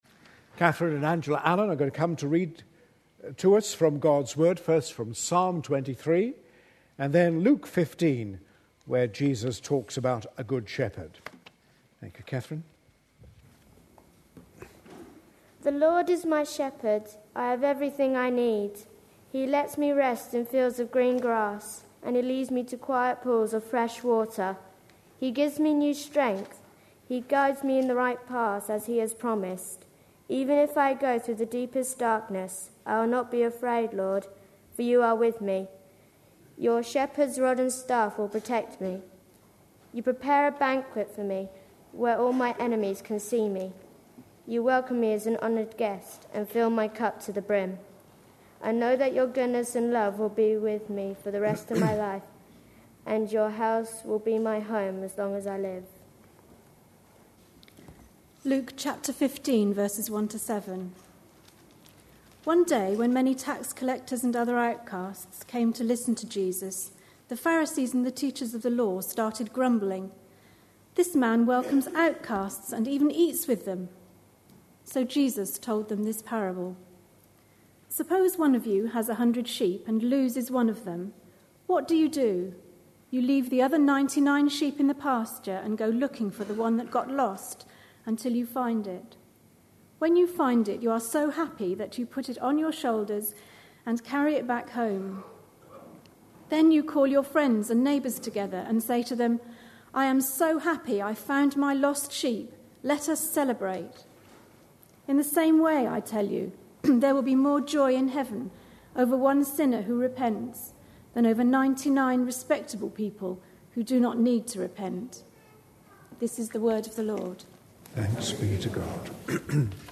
A sermon preached on 7th August, 2011, as part of our Psalms we Love series.